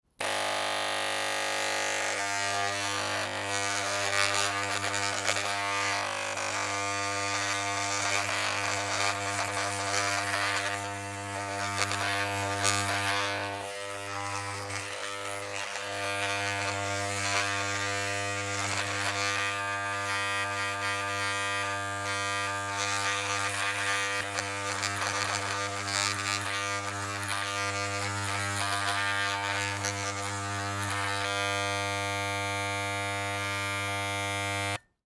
Braun Sixtant shaver